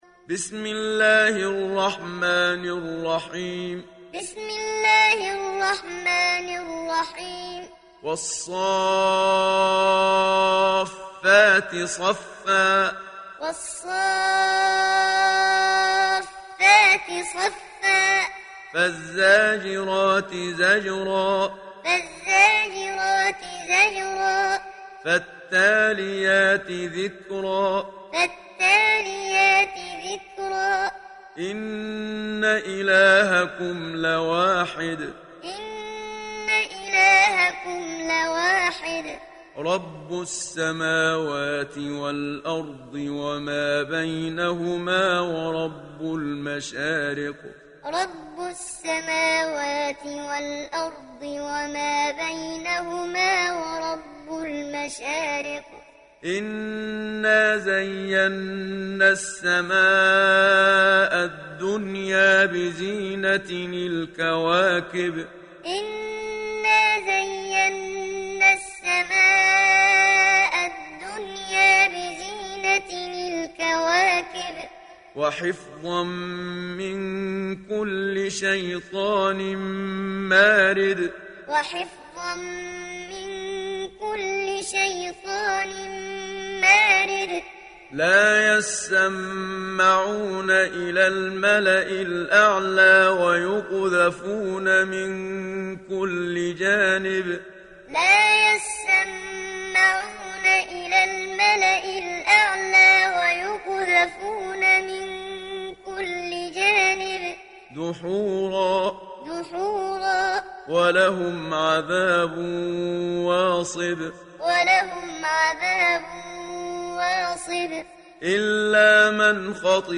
Surah As Saffat Download mp3 Muhammad Siddiq Minshawi Muallim Riwayat Hafs from Asim, Download Quran and listen mp3 full direct links
Muallim